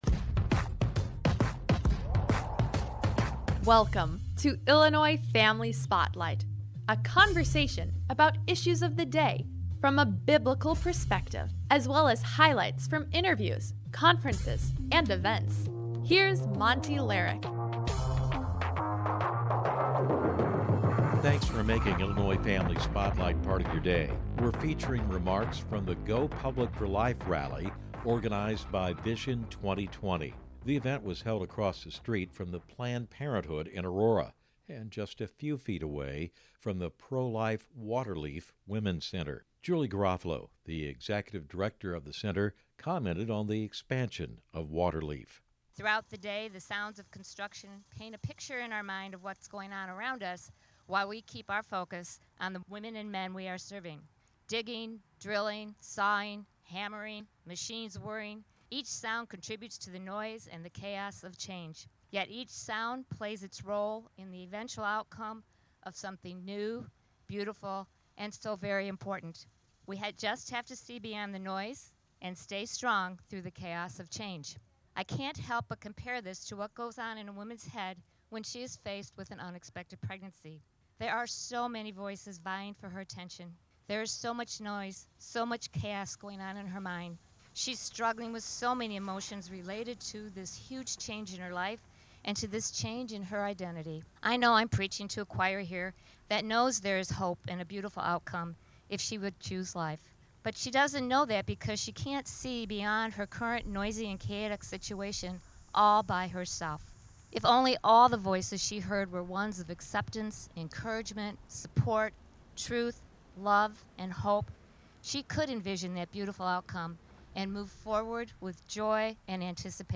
We’re featuring remarks from the Go Public for Pro Life rally, organized by Vision 2020. It was held across the street from the Planned Parenthood mill in Aurora, Illinois.